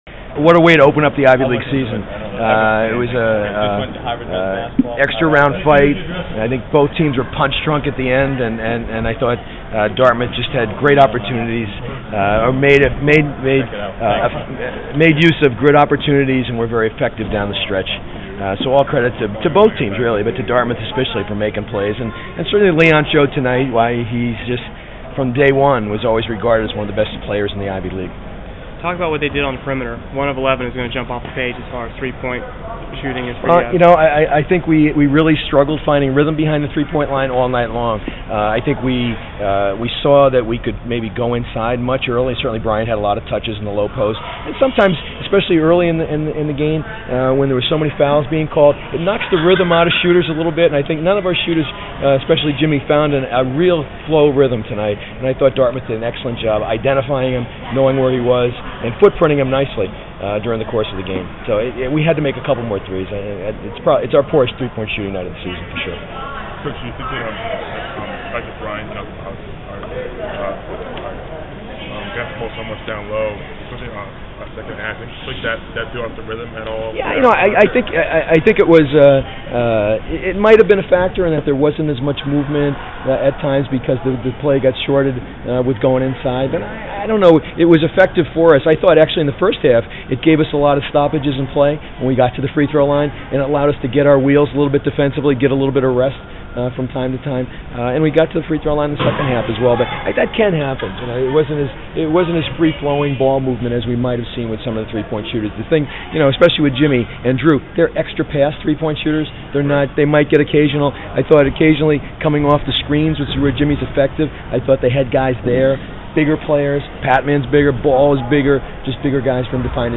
Postgame audio